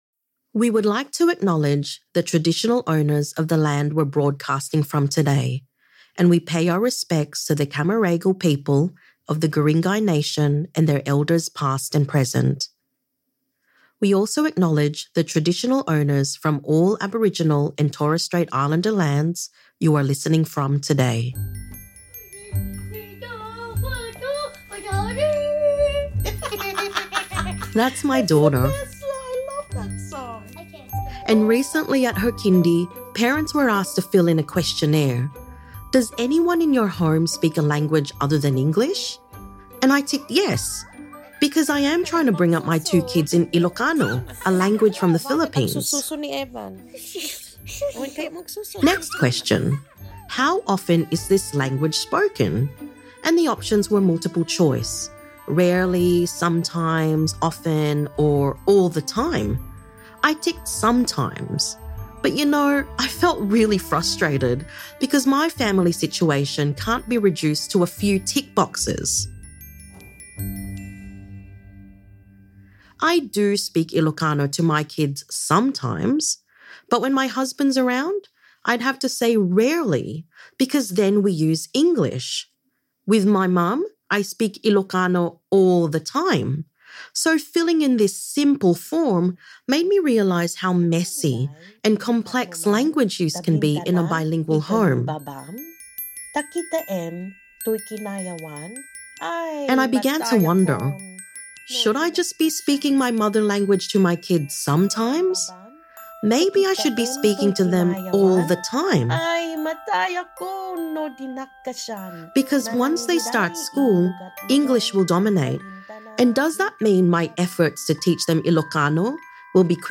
In this episode we talk to speech pathologist